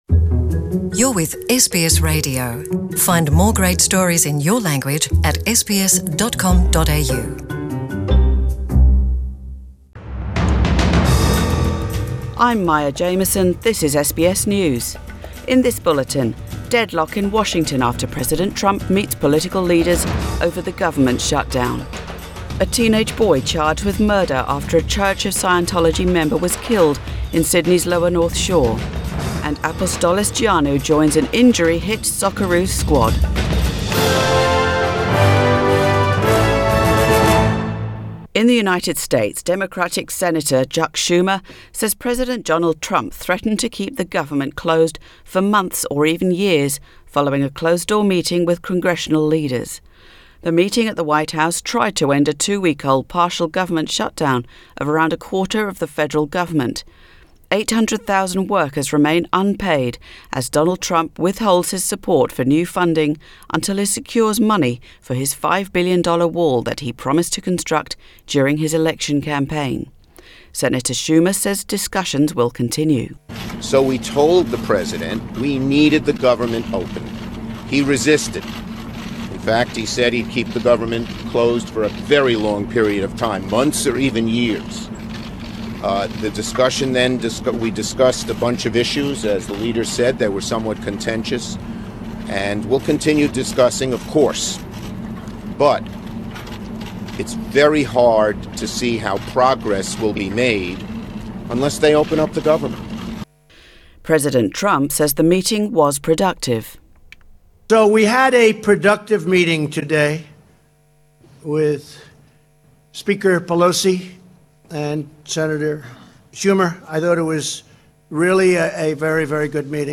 0800 Bulletin 5 Jan